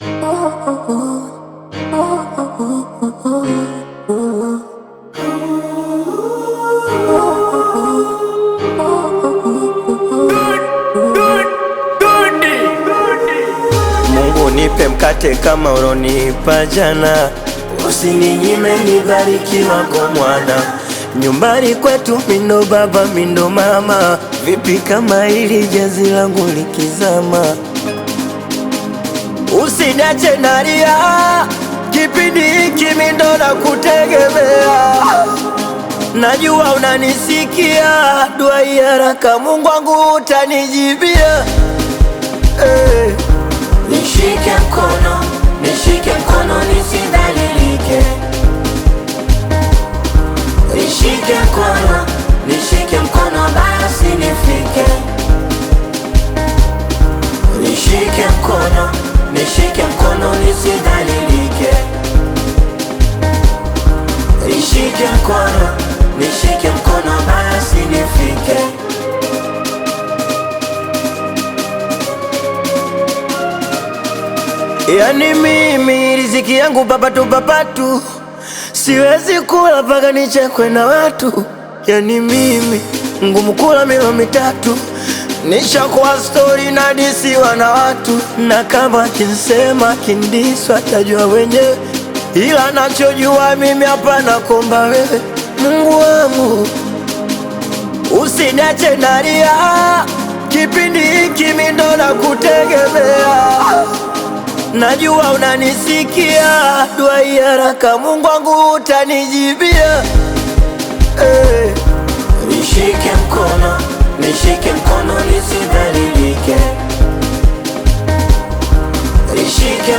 AudioSingeli
heartfelt Bongo Flava single